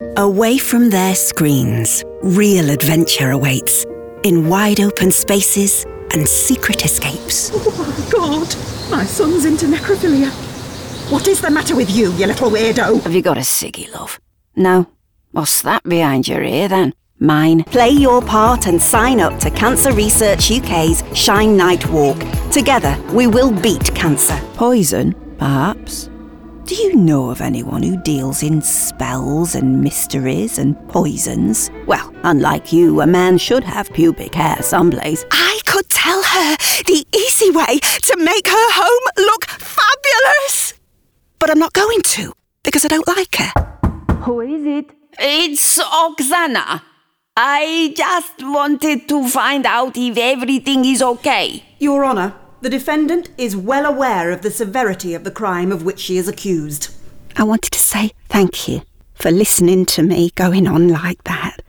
English (British)